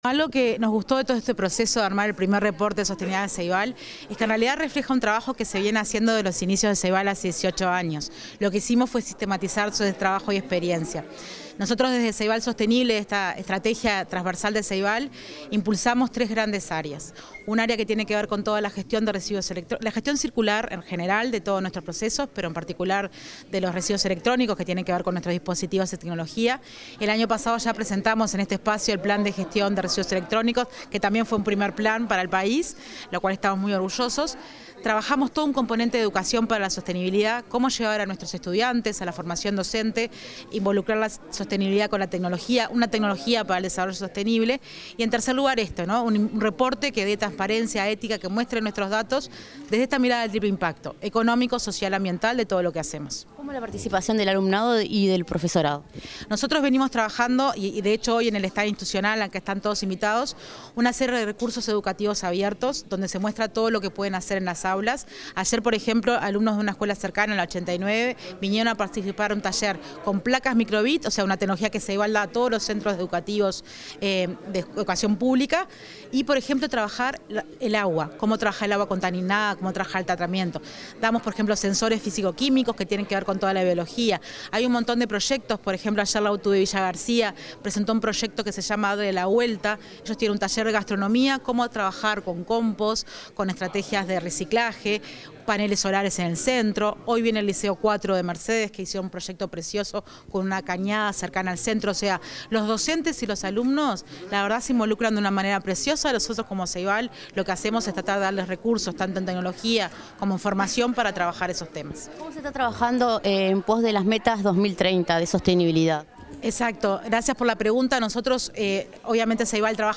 declaraciones a la prensa tras la presentación del reporte sobre sostenibilidad en la Expo Uruguay Sostenible.